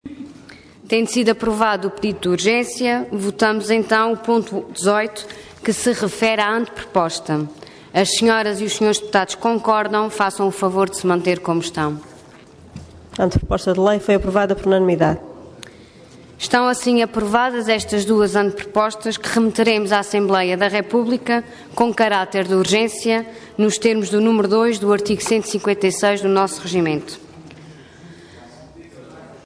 Intervenção Anteproposta de Lei Orador Ana Luísa Luís Cargo Presidente da Assembleia Regional Entidade Autores Vários